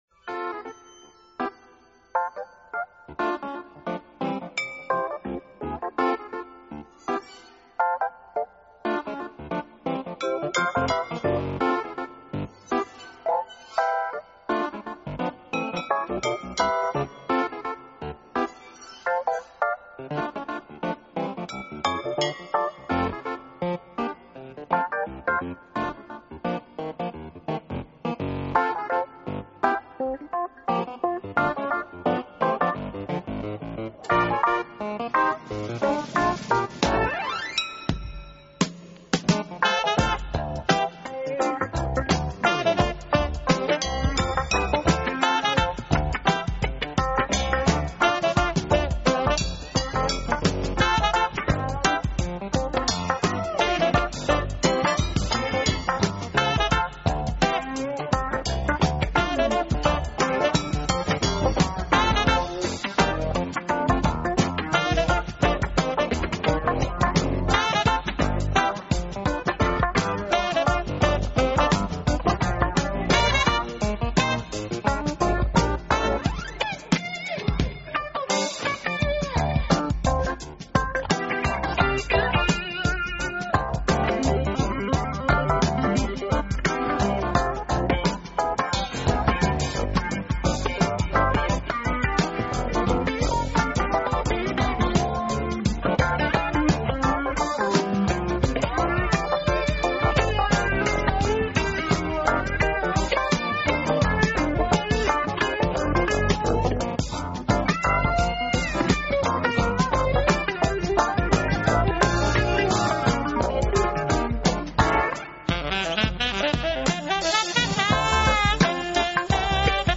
【爵士吉他】
他的風格是屬於Soft Jazz，一種輕鬆愉快弦律的融合爵士樂，自幼就生長在陽光普照